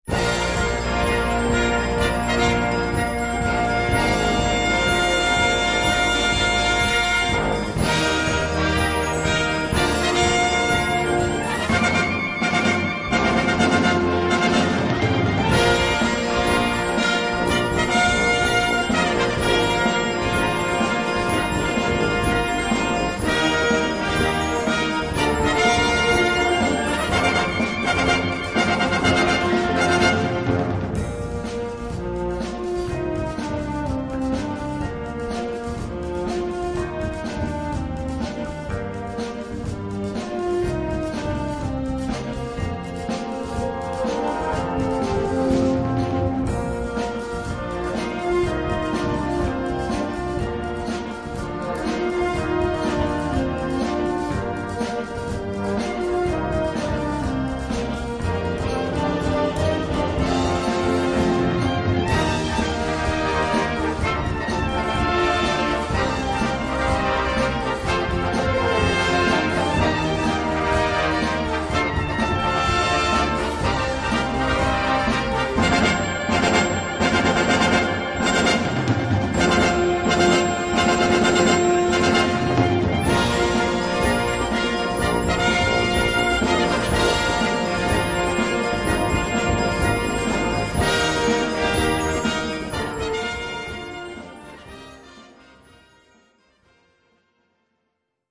Gattung: Rock-Opening or Ending
Besetzung: Blasorchester